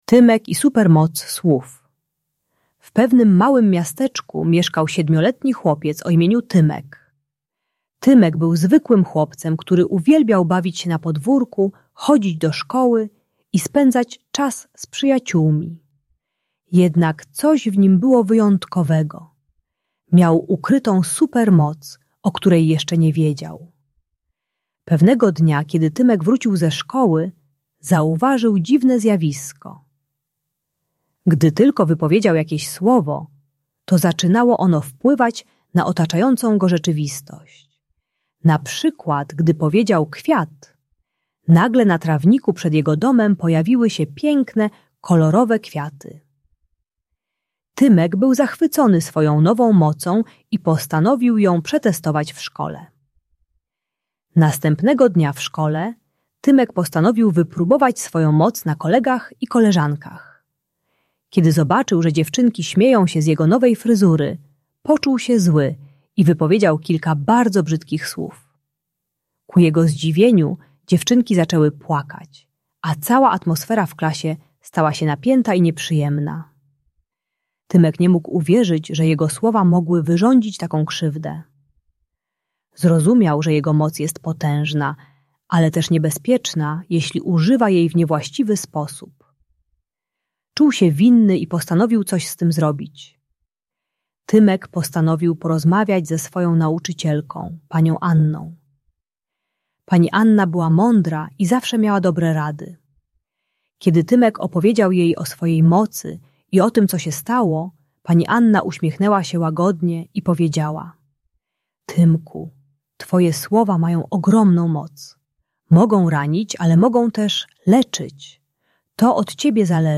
Audiobajka o mocy słów uczy techniki "słowa jak nasiona" - zanim powiesz coś złego, pomyśl czy chcesz zasiać kwiaty czy kolce.